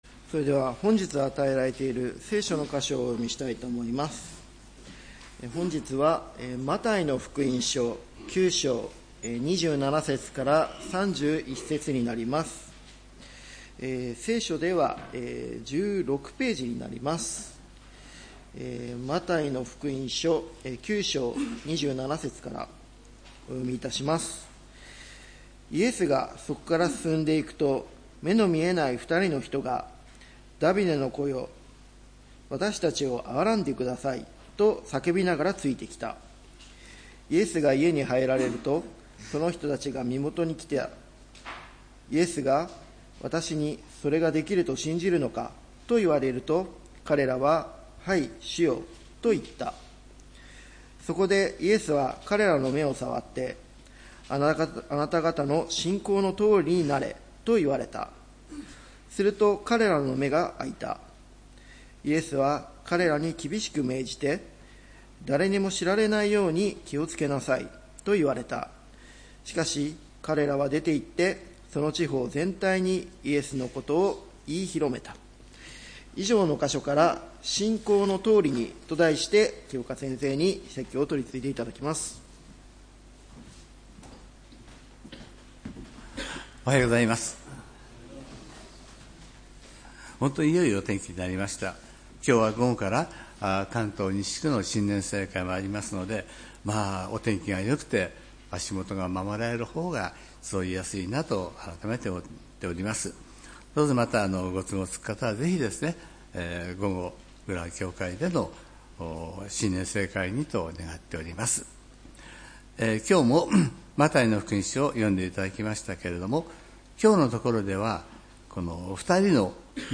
礼拝メッセージ「信仰の通りに」（1月18日）